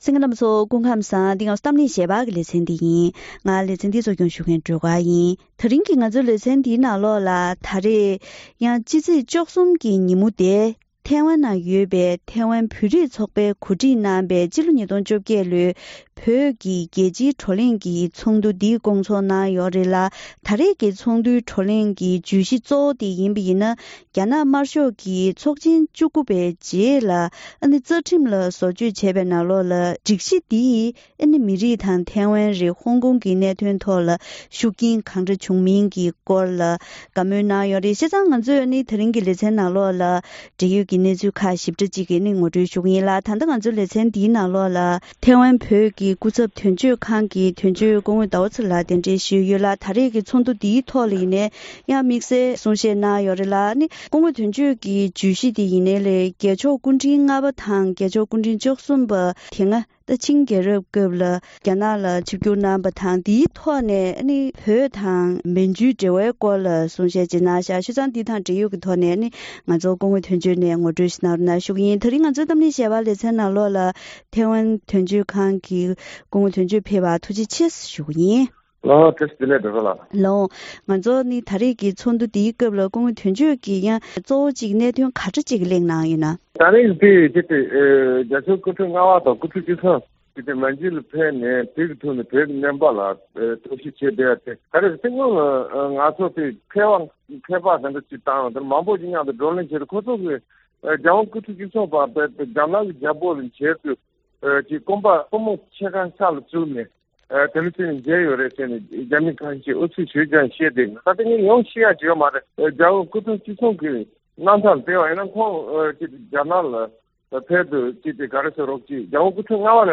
ཐེངས་འདིའི་གཏམ་གླེང་ཞལ་པར་ལེ་ཚན་ནང་ཐེ་ཝན་གྱི་བོད་རིགས་ཚོགས་པས་གོ་སྒྲིག་གནང་བའི་སྤྱི་ལོ་༢༠༡༨ལོའི་བོད་དོན་སྐོར་གྱི་རྒྱལ་སྤྱིའི་བགྲོ་གླེང་ཚོགས་འདུར་མཉམ་ཞུགས་གནང་མཁན་ཁག་ཅིག་དང་ལྷན་དུ་བོད་ཀྱི་ལོ་རྒྱུས་སྐོར་དང་། བོད་ཀྱི་ད་ལྟའི་གནས་སྟངས། བོད་ཀྱི་ཁོར་ཡུག་གནད་དོན། ཐེ་ཝན་དང་ཧོང་ཀོང་གི་གནས་སྟངས་ཐོག་ནས་བོད་དོན་གླེང་པ་སོགས་ཀྱི་སྐོར་ལ་བཀའ་མོལ་ཞུས་པ་ཞིག་གསན་རོགས་གནང་།